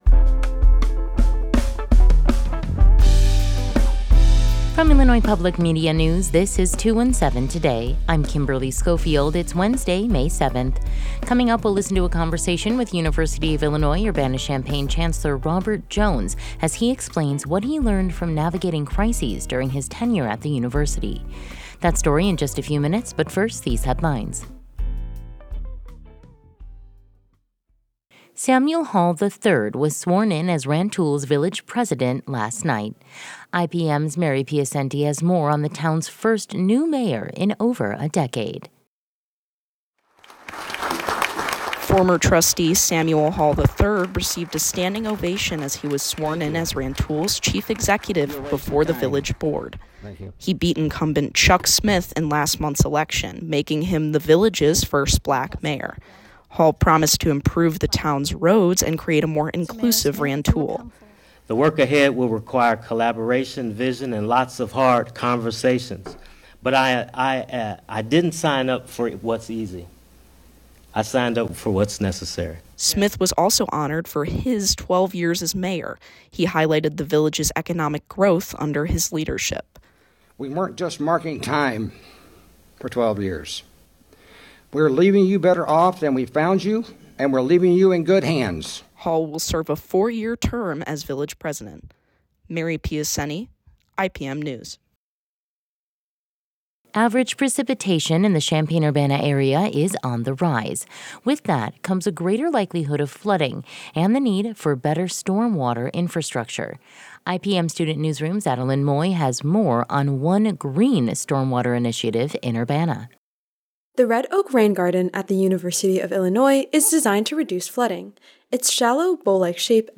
In today's deep dive, we’ll listen to a conversation with University of Illinois Urbana-Champaign Chancellor Robert Jones, who explains what he learned from navigating crises during his tenure at the university.